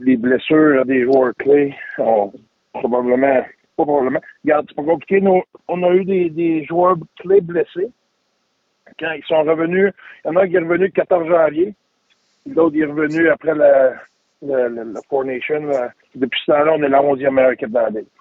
Le coach a dit quelques mots la dernière campagne.